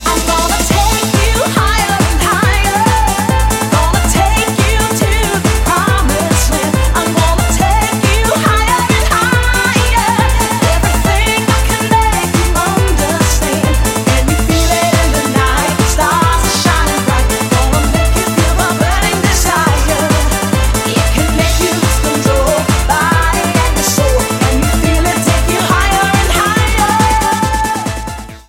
• Качество: 128, Stereo
евродэнс